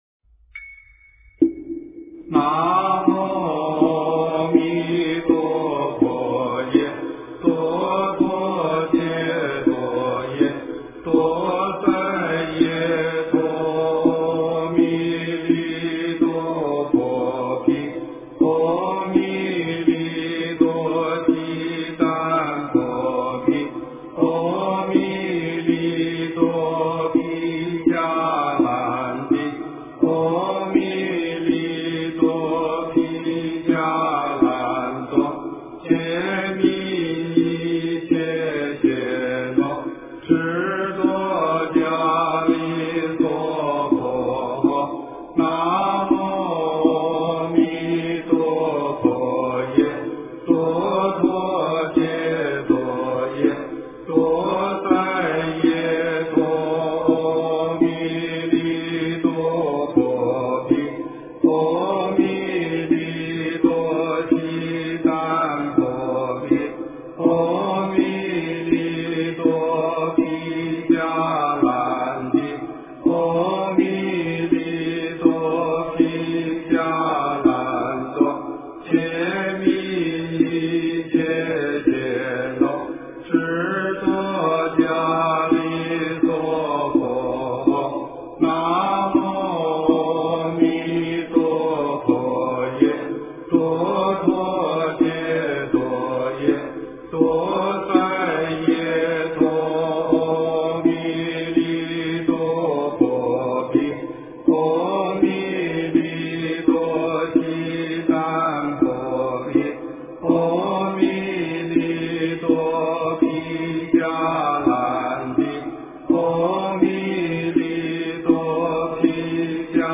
经忏
佛音 经忏 佛教音乐 返回列表 上一篇： 早课--辽宁万佛禅寺 下一篇： 观世音菩萨白佛言--佛光山梵呗团 相关文章 南无本师释迦摩尼佛.心经--佛光山梵呗团 南无本师释迦摩尼佛.心经--佛光山梵呗团...